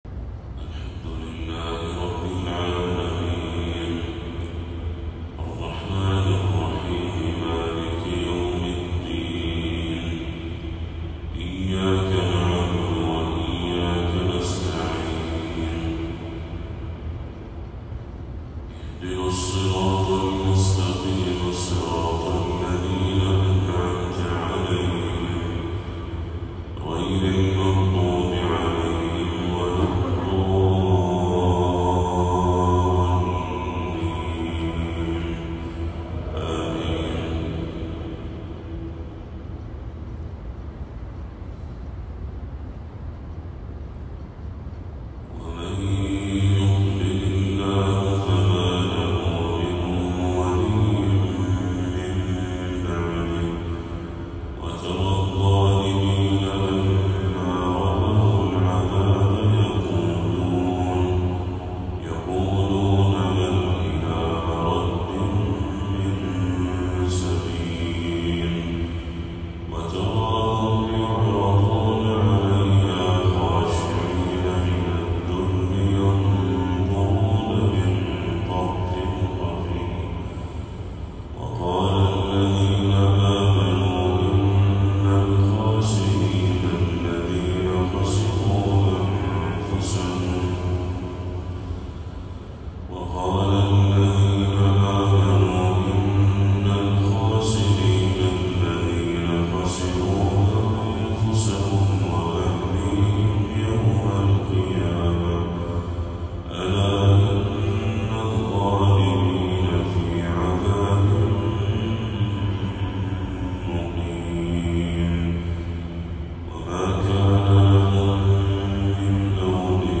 تلاوة هادئة لخواتيم سورة الشورى